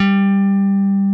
G3 2 F.BASS.wav